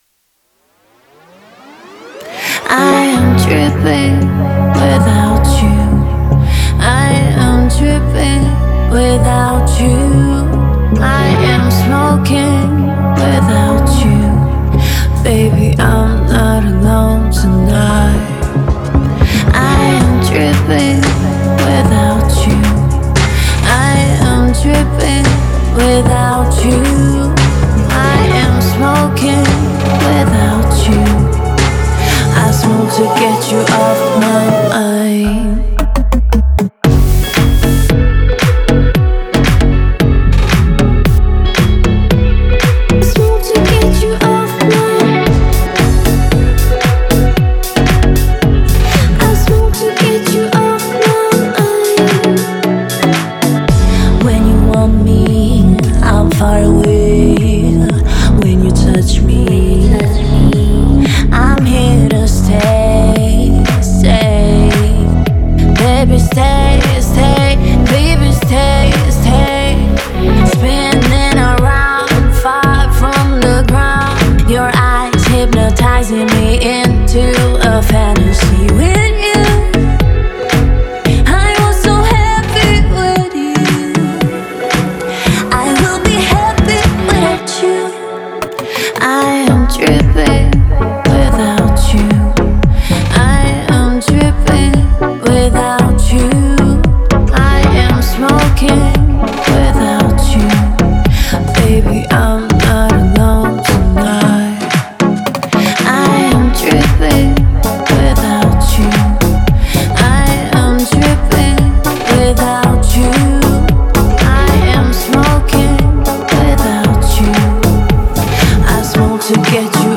это энергичная композиция в жанре хип-хоп
В песне звучит уверенный и живой ритм